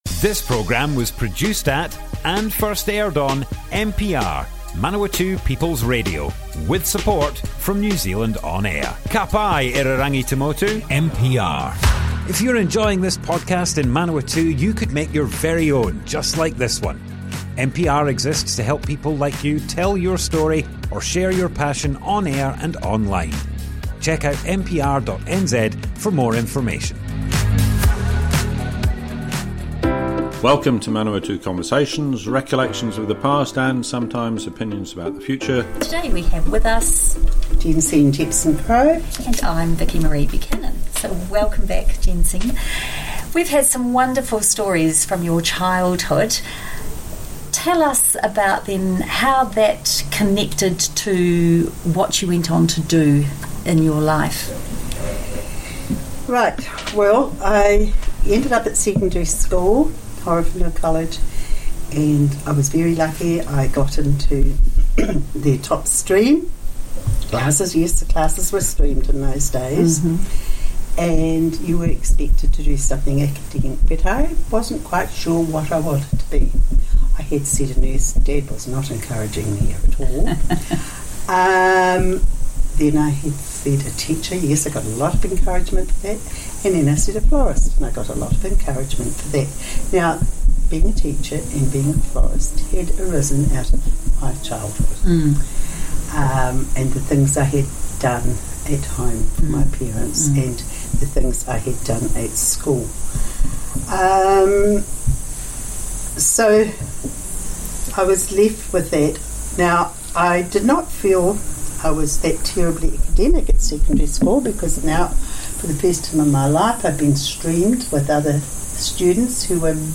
Manawatu Conversations More Info → Description Broadcast on Manawatu People's Radio, 7th March 2023.
oral history